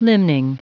Prononciation du mot limning en anglais (fichier audio)
Prononciation du mot : limning